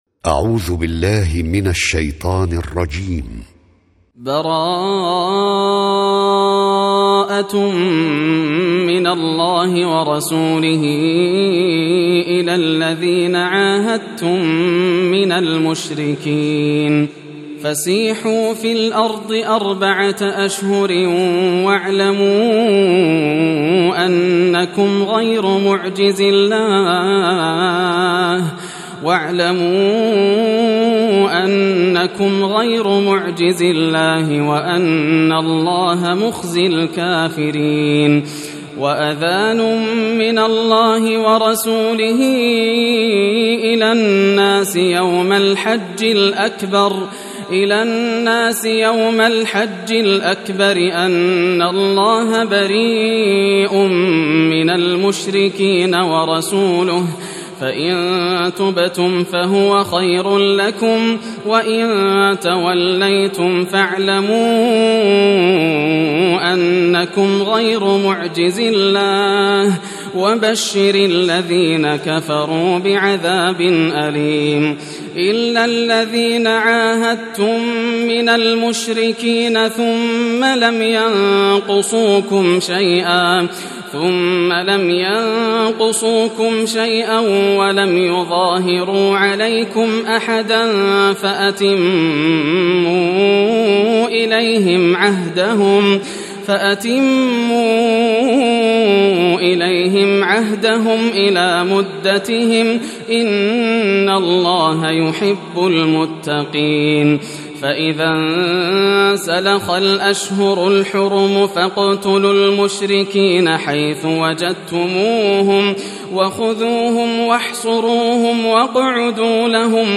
سورة التوبة > المصحف المرتل للشيخ ياسر الدوسري > المصحف - تلاوات الحرمين